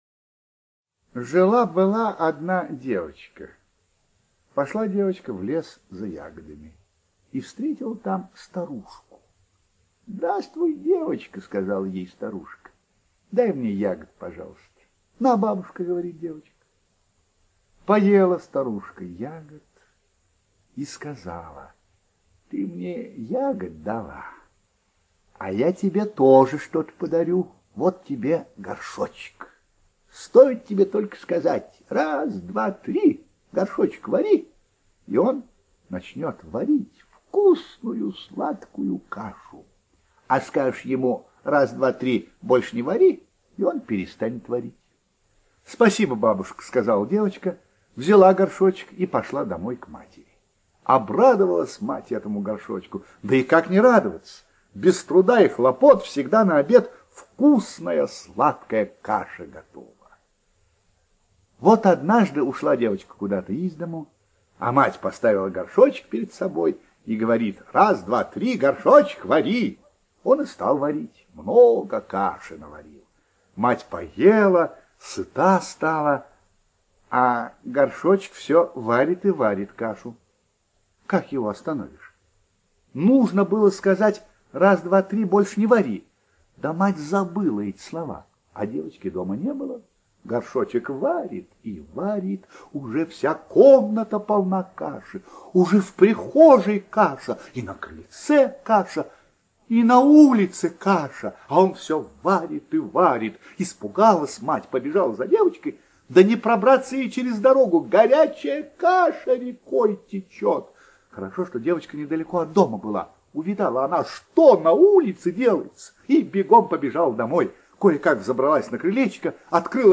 ЧитаютПлятт Р., Рунге Б., Менглет Г.